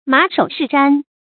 注音：ㄇㄚˇ ㄕㄡˇ ㄕㄧˋ ㄓㄢ
馬首是瞻的讀法